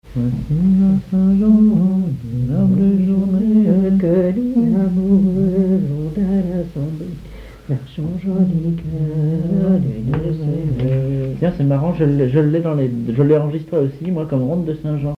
chanteur(s), chant, chanson, chansonnette
Genre strophique
Pièce musicale inédite